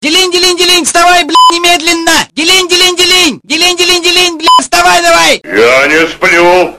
Назад в Голоса Файл Budilnik.mp3 (2) Листать файлы Добавил Это не Adult файл Файл проверен от 14.10.2007, вирусов нет Скачать (107.3кб/07с.
Budilnik.mp3